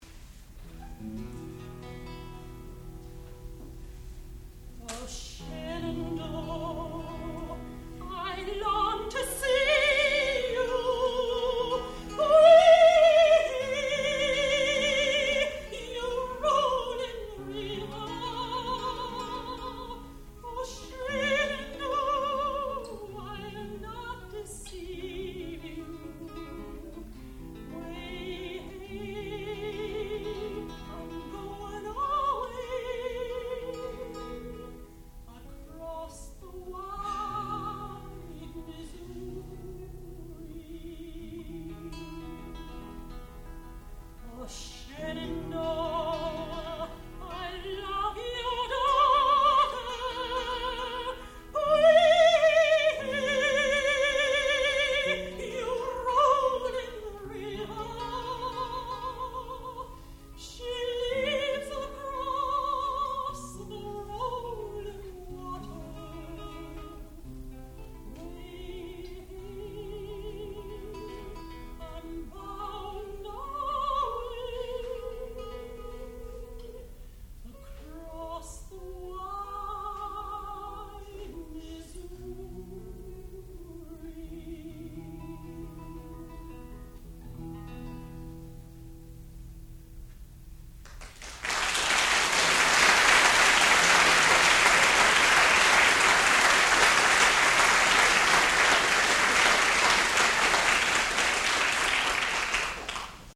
American folk songs
dulcimer, guitar and banjo
soprano